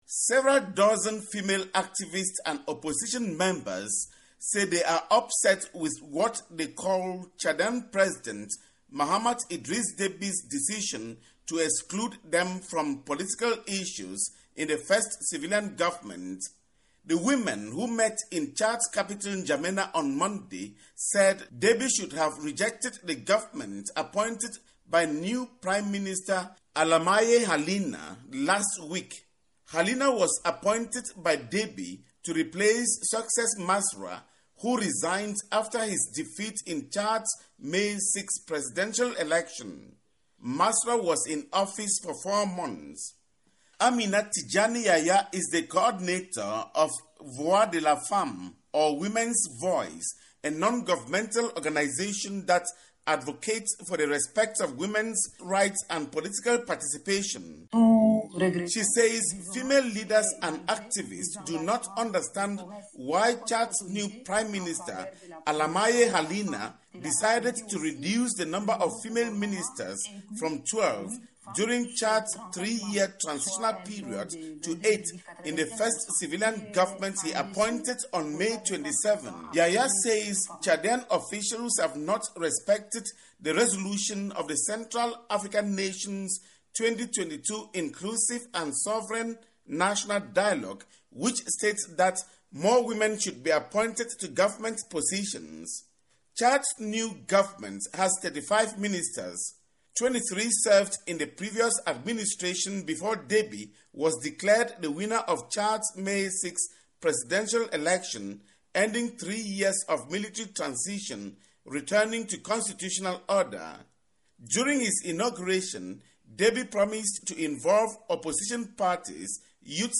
reports from neighboring Cameroon